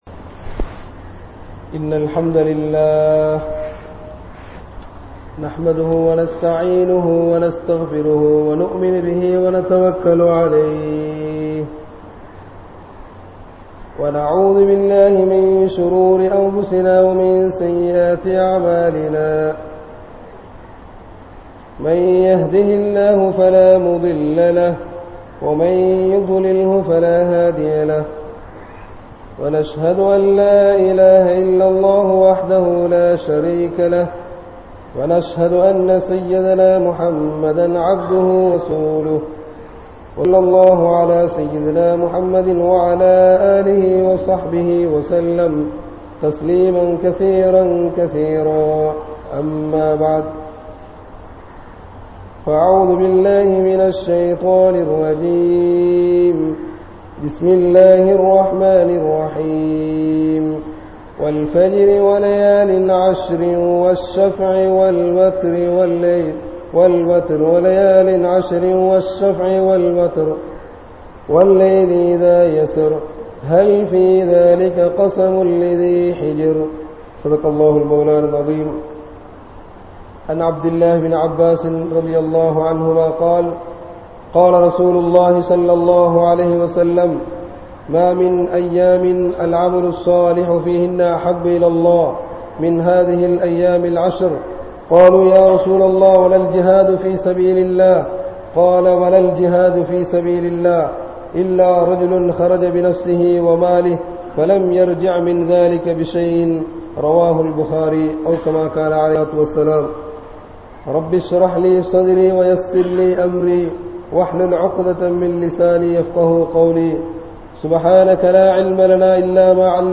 First 10 Days of Dhul Hijjah | Audio Bayans | All Ceylon Muslim Youth Community | Addalaichenai